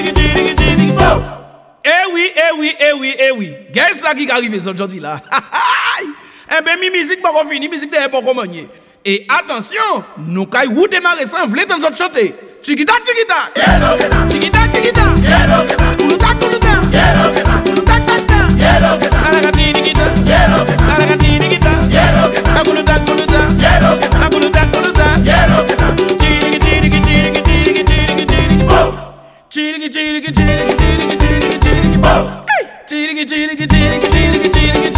# Worldbeat